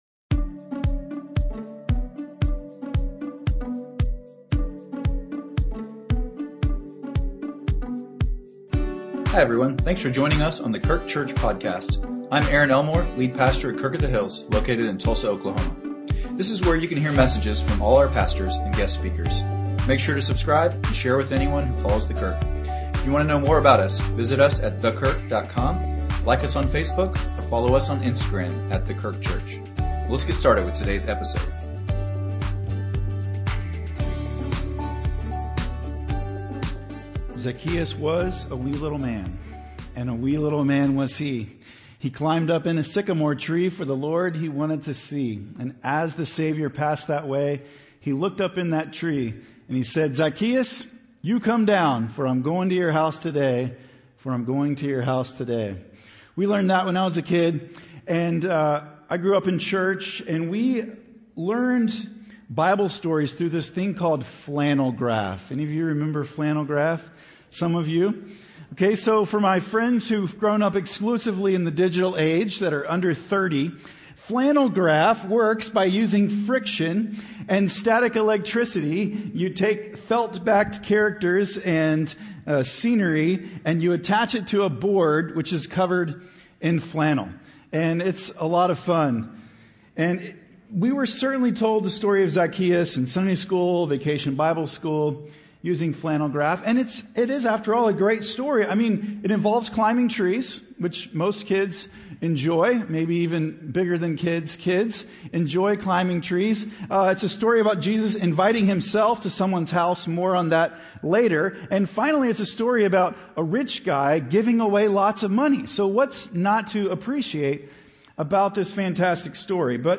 Sermons | The Kirk - Tulsa, OK